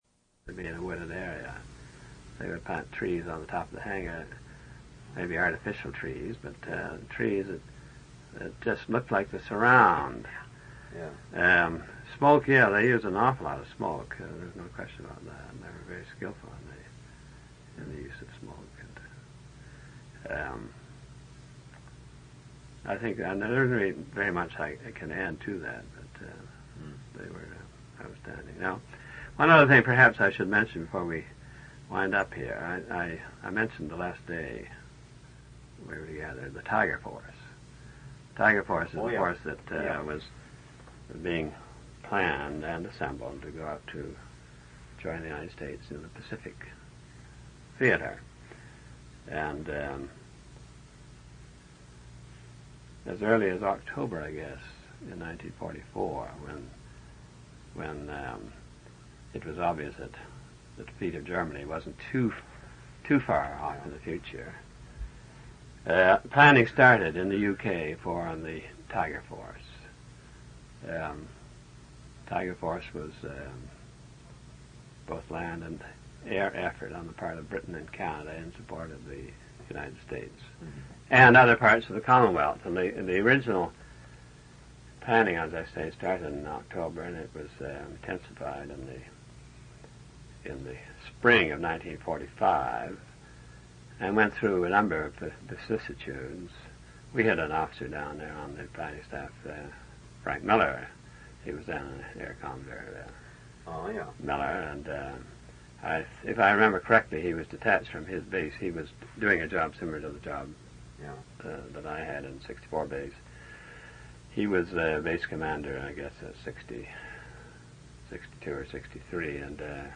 Interview took place on July 2, 8, 16, 23, 30, August 20 and September 3, 1981.